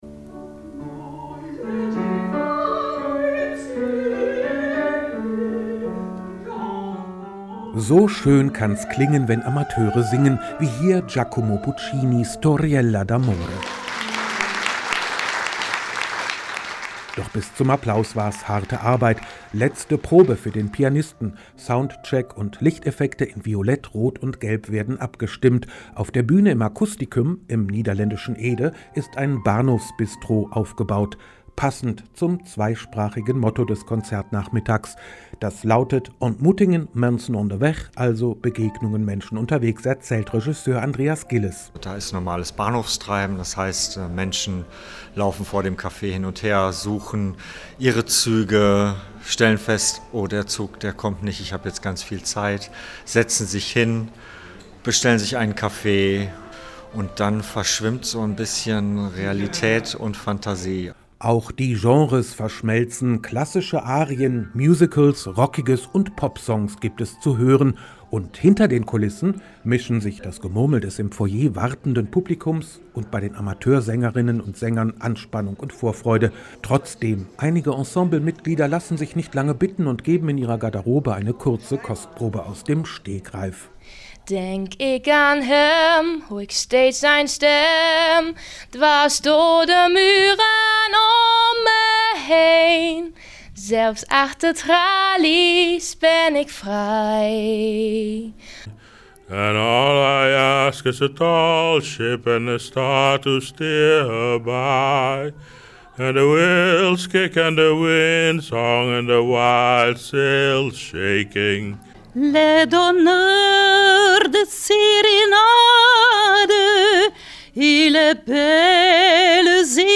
WDR Reportage 2025